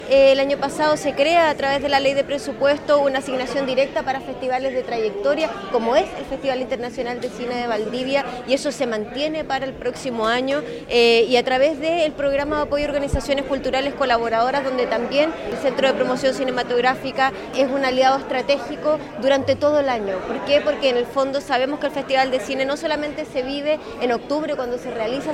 La ministra de las Culturas, Carolina Arredondo, presente en la inauguración, detalló de qué forma apoyan la realización del Festival de Cine.